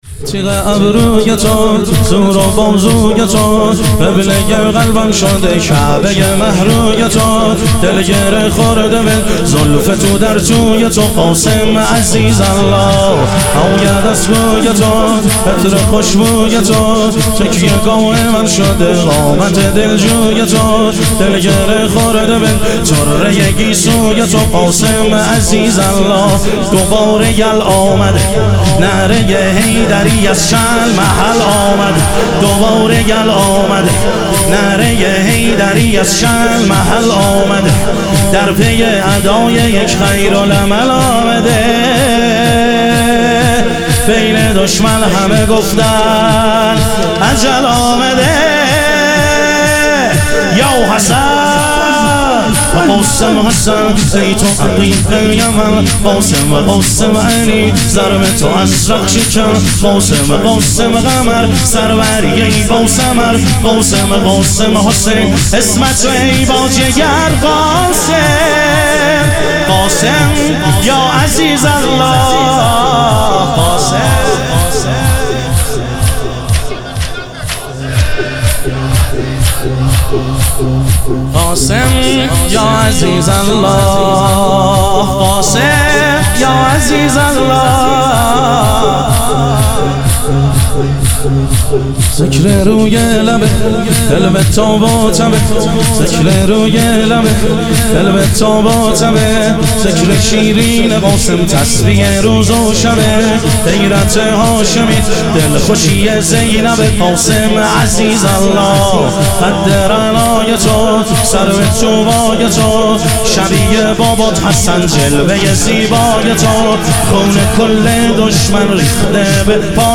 شهادت حضرت سلطانعلی علیه السلام - شور